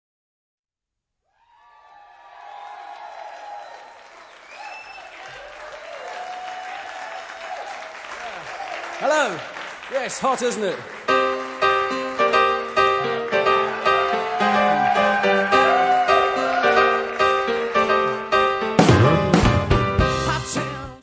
piano
baixo
bateria.
: stereo; 12 cm
Music Category/Genre:  Pop / Rock